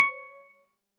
clickpagesound.mp3